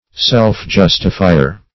Search Result for " self-justifier" : The Collaborative International Dictionary of English v.0.48: Self-justifier \Self`-jus"ti*fi`er\, n. One who excuses or justifies himself.